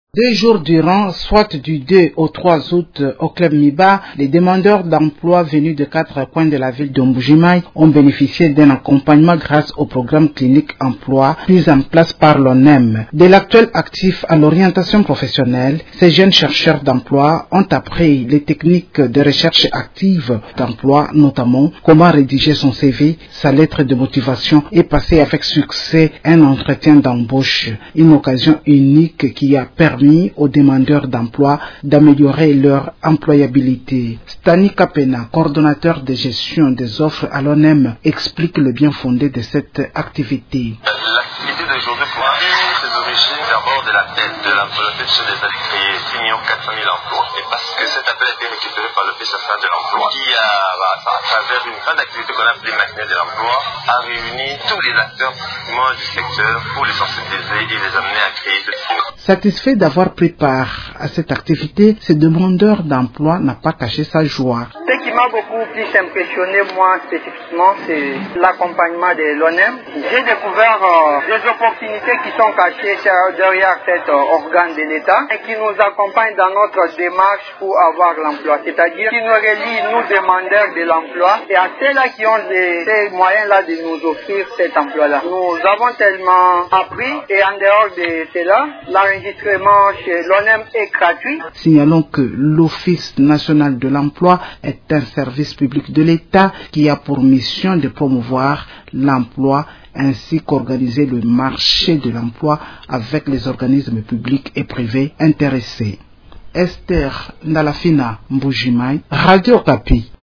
Les détails dans ce reportage